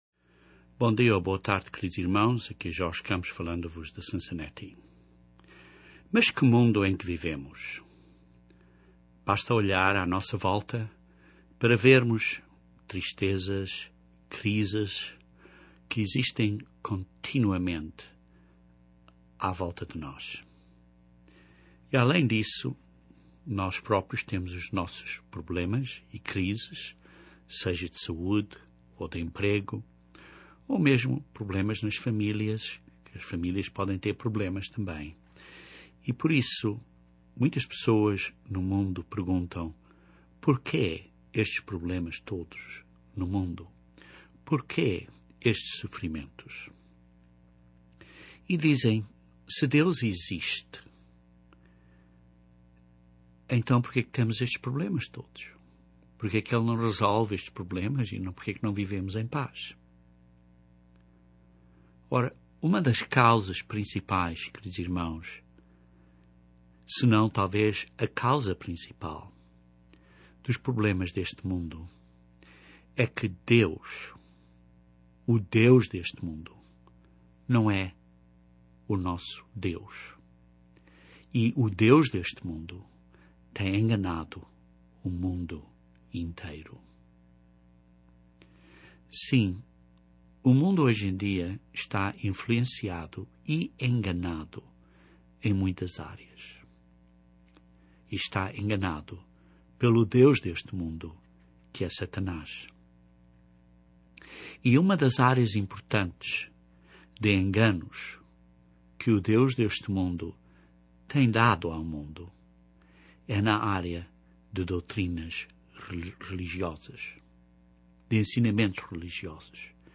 Este sermão inicia uma séria de sermões a cubrir as doutrinas enganadoras de Satanás.